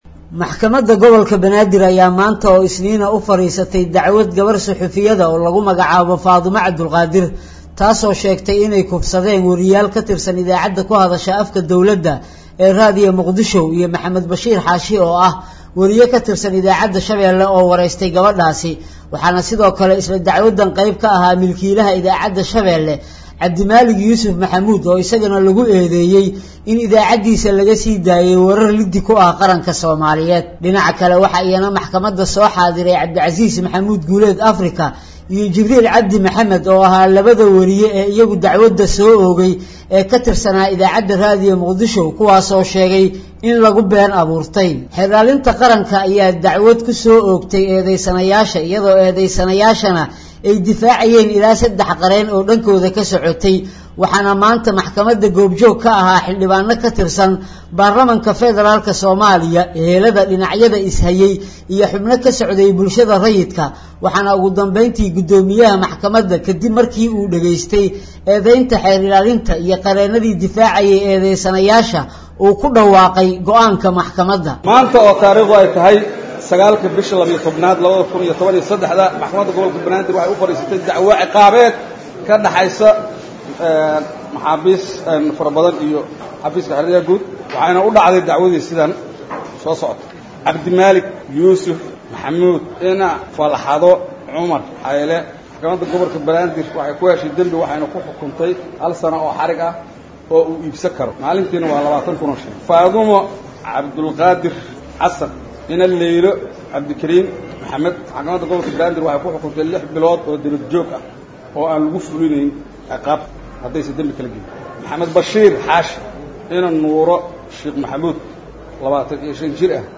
Warbixinta Xukunka lagu riday Wariyayaasha